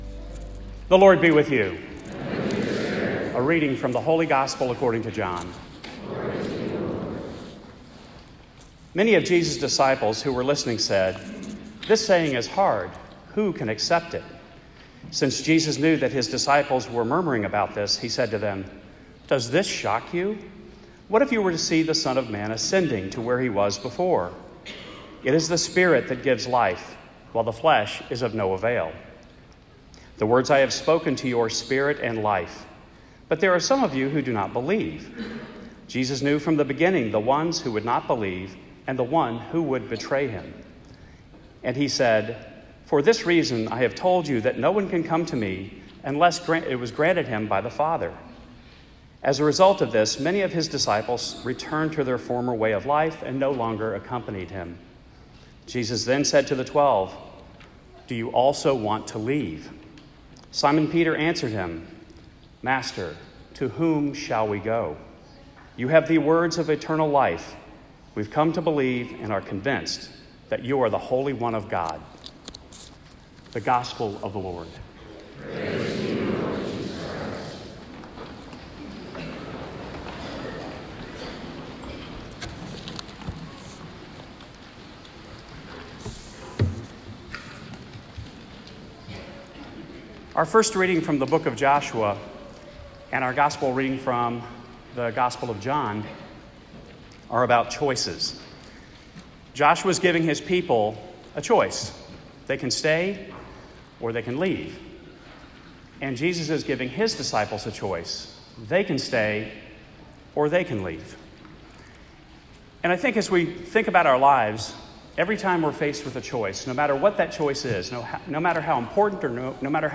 I addressed this topic in my homily yesterday. It is clear to me that the laity must force reform and take an active role in directing it.